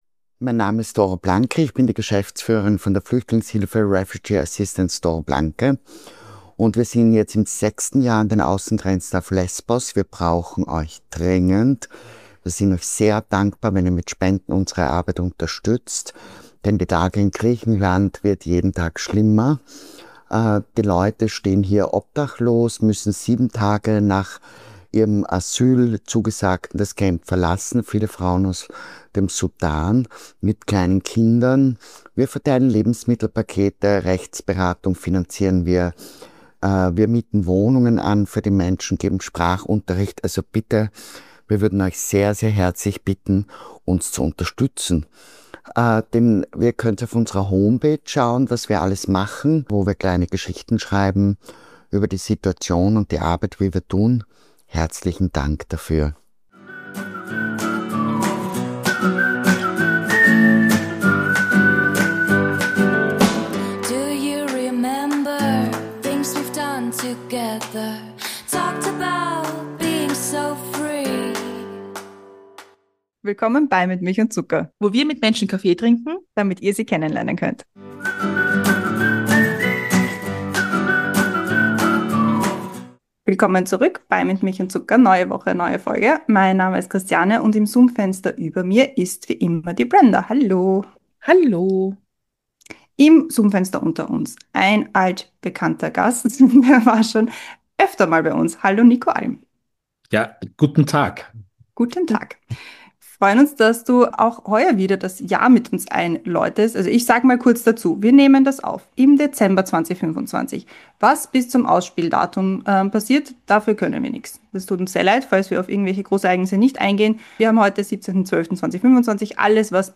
Ein Gespräch über das kommende Jahr, Europa, die österreichische Medienlandschaft, Musik und darüber, was passieren sollte.